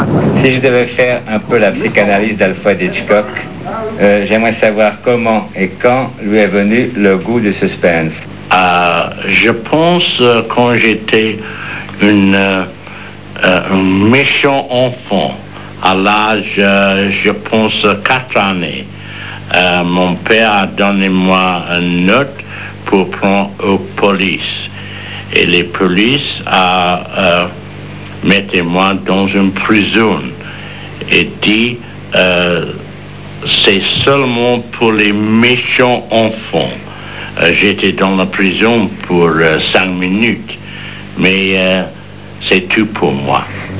Des extraits d'interviews
Comme vous pourrez l'entendre, Hitchcock avait la particularité d'avoir un débit de parole extrêmement lent, à la limite du crispant pour un anglophone mais bien agréable à écouter pour les malheureux francophones que nous sommes...